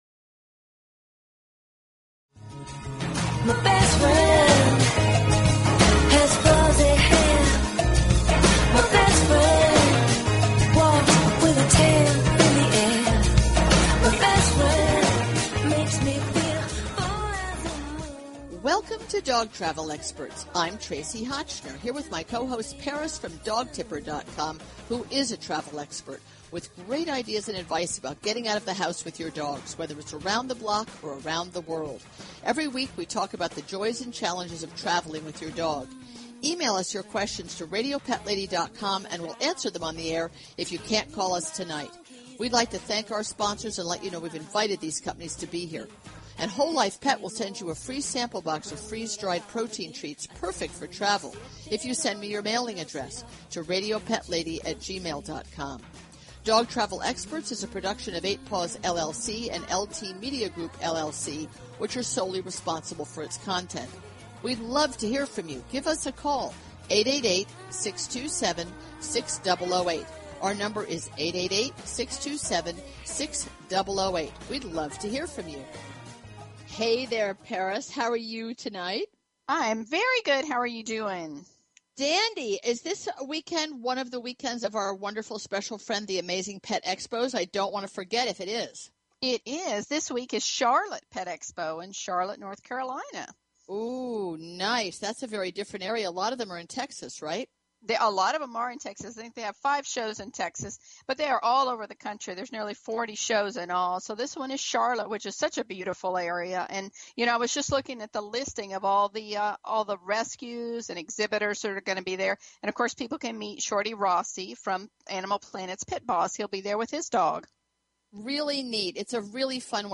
Talk Show Episode, Audio Podcast, Dog_Travel_Experts and Courtesy of BBS Radio on , show guests , about , categorized as